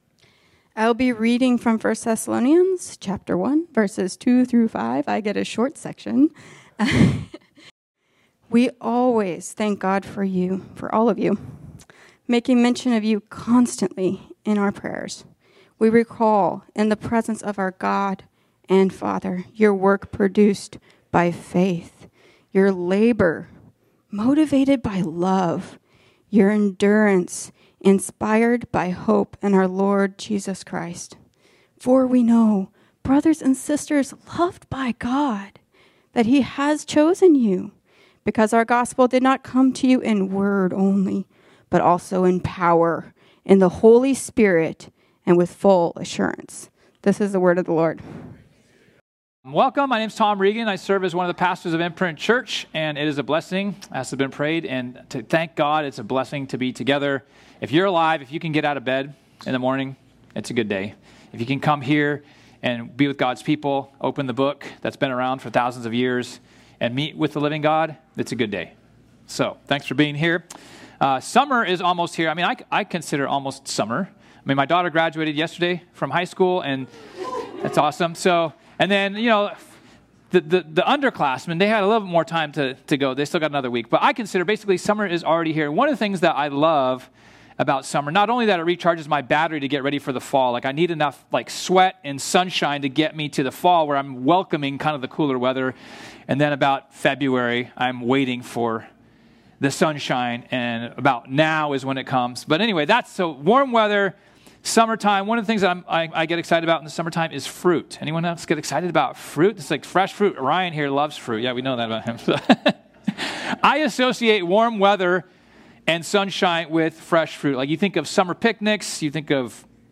This sermon was originally preached on Sunday, June 8, 2025.